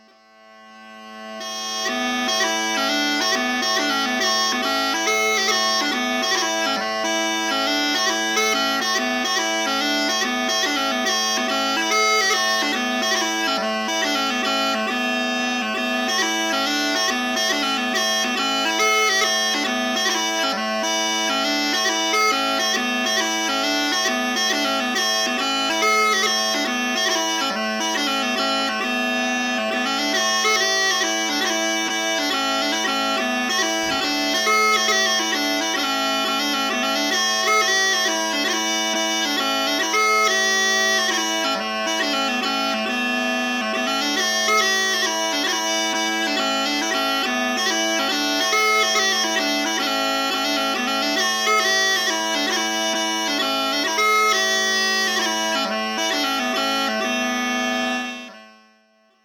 Category: Reel Tag: 4/4